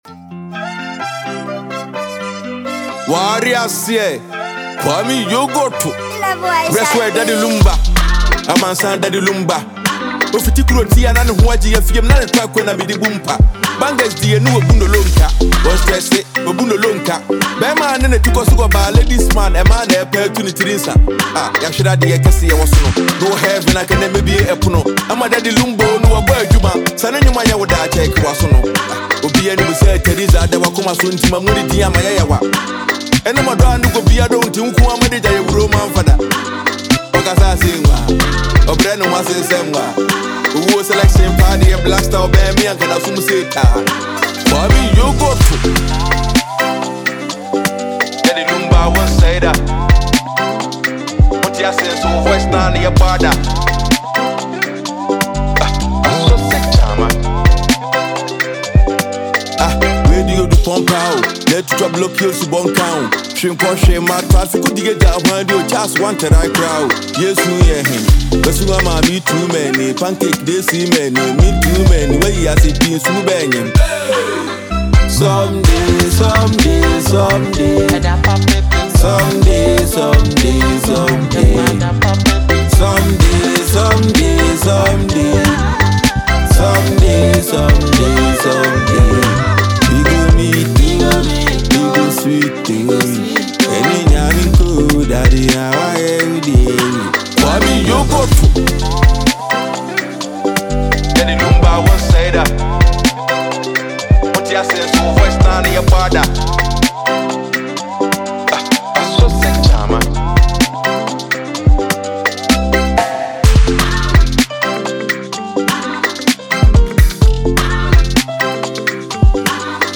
Rising Ghanaian rapper
With soulful lyrics, reverent tone, and nostalgic melody